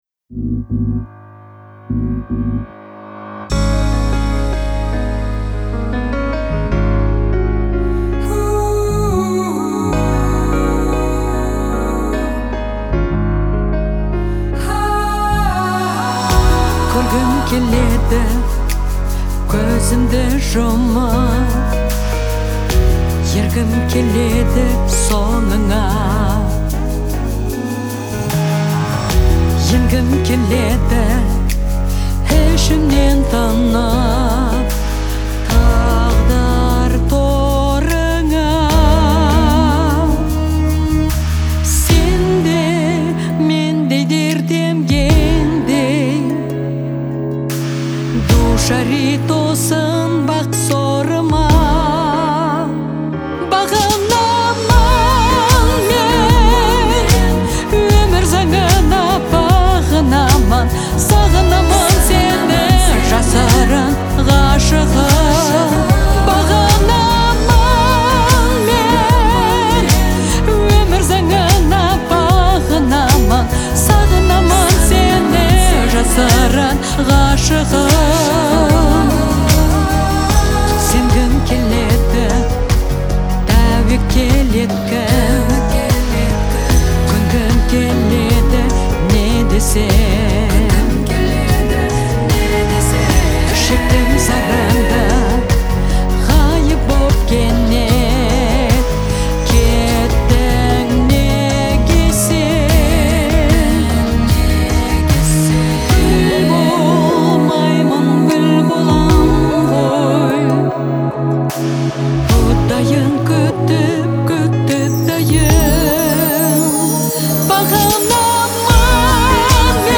в жанре поп
её голос передает всю глубину переживаний и размышлений.